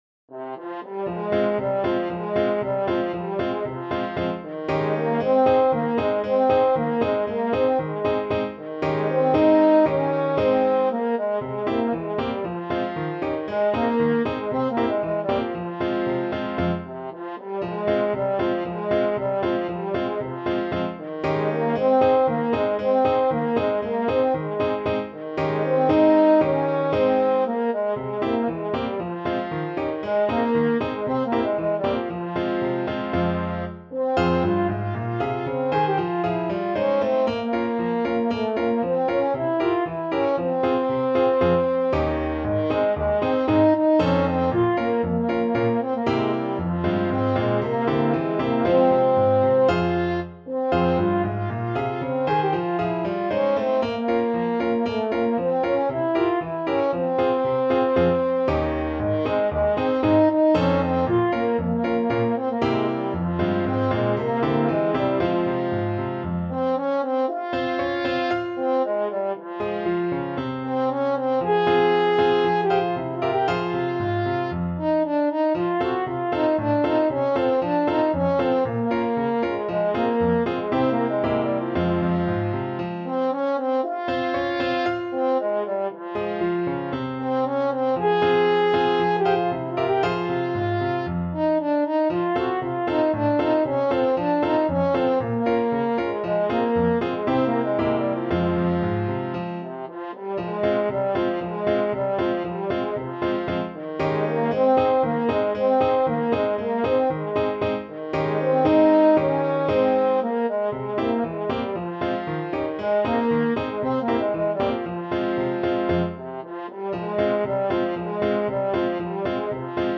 French Horn
F minor (Sounding Pitch) C minor (French Horn in F) (View more F minor Music for French Horn )
Allegro =c.116 (View more music marked Allegro)
Traditional (View more Traditional French Horn Music)